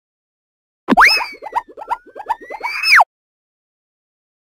Cartoon Spin Sound
cartoon